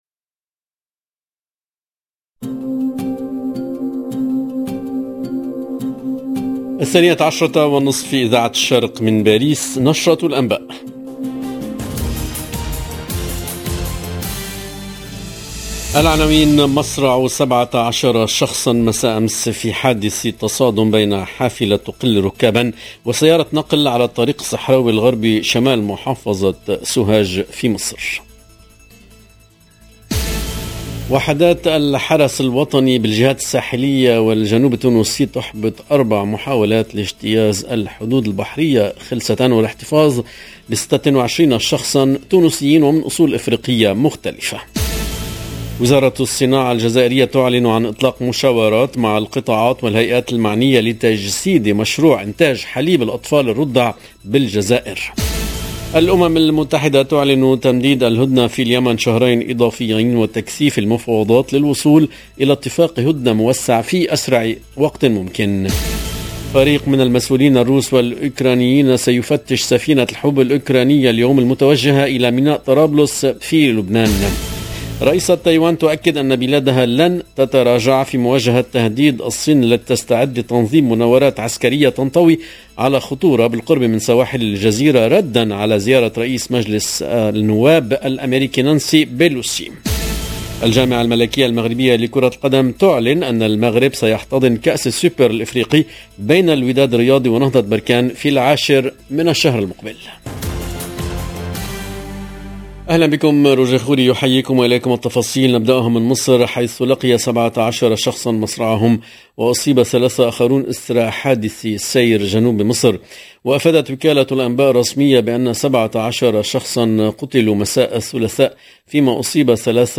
LE JOURNAL EN LANGUE ARABE DE MIDI 30 DU 3/08/22